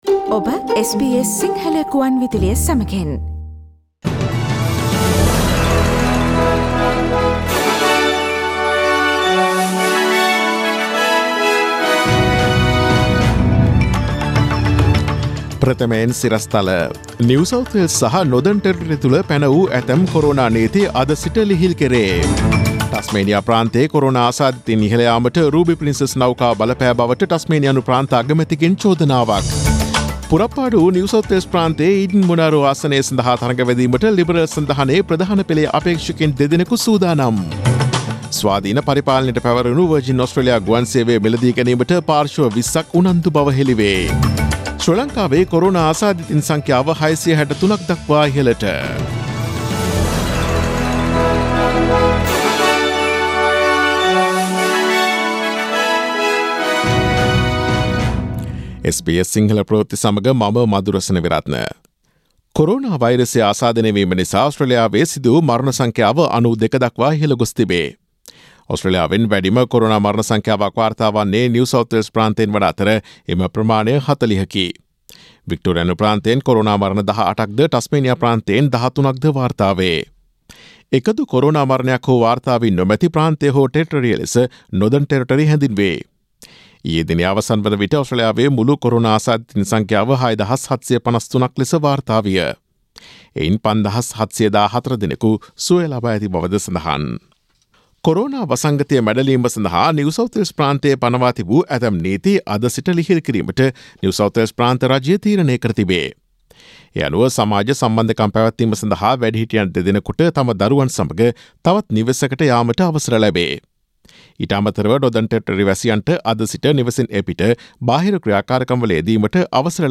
Daily News bulletin of SBS Sinhala Service: Friday 01 May 2020